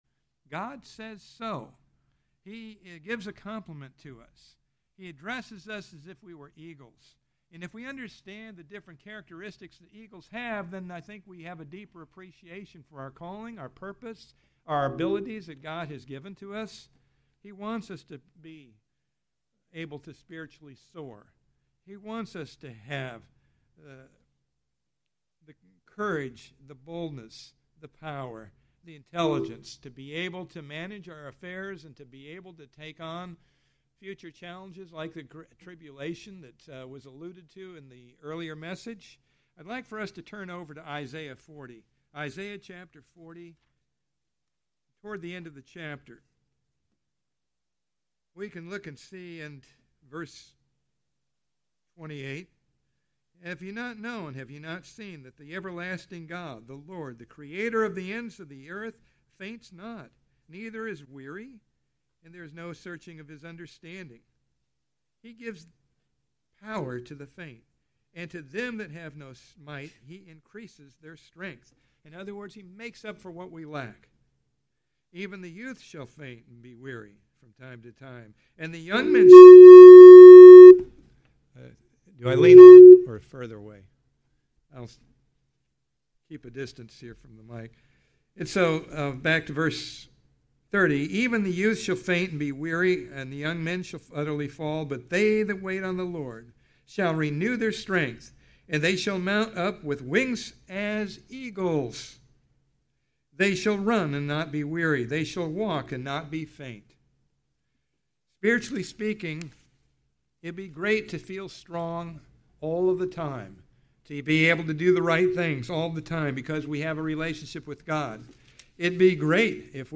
Listen to this Sermon and fly like an Eagle